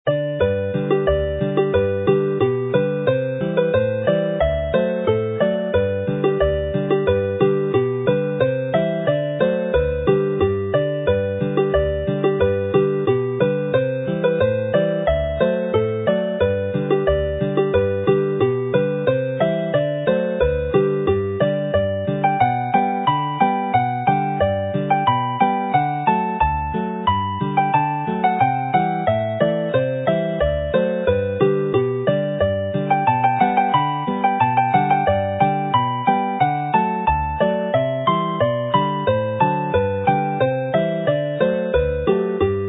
Alawon Cymreig - Set Joio / Having fun - Welsh folk tunes to play